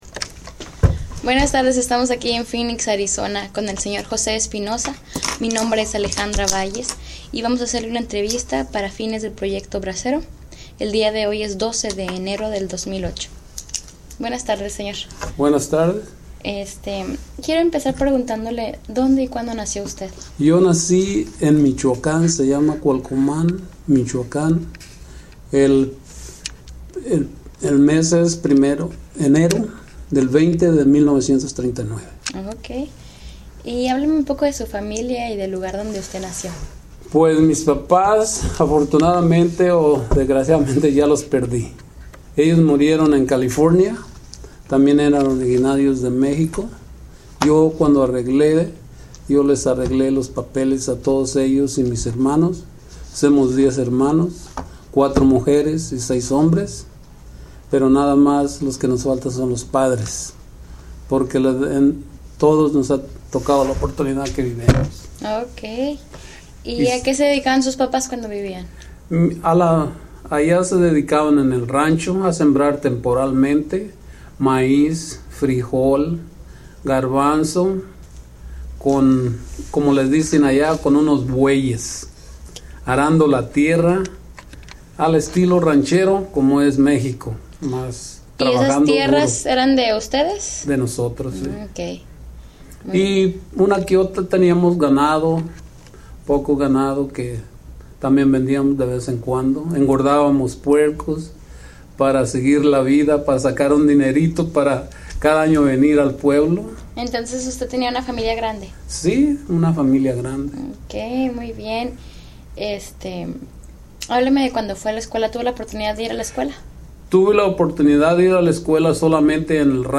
Location Phoenix, Arizona